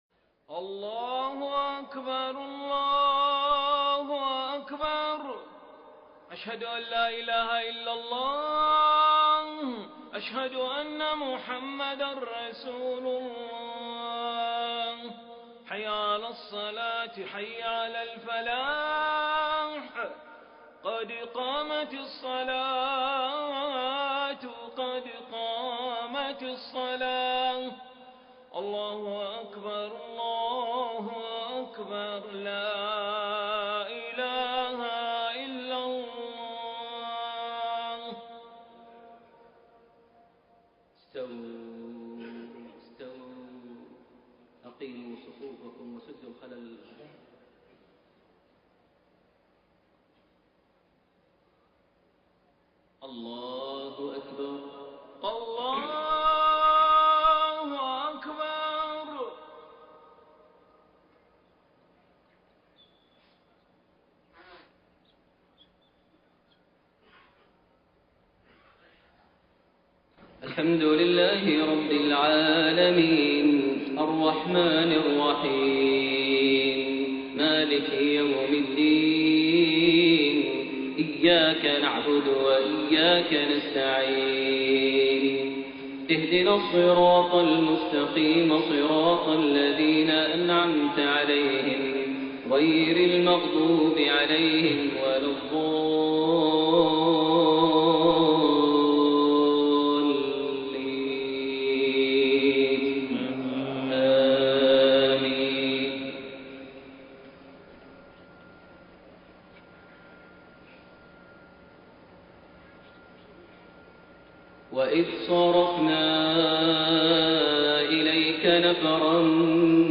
Maghrib prayer from Surah Al-Ahqaf > 1433 H > Prayers - Maher Almuaiqly Recitations